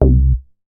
MoogPoint 003.WAV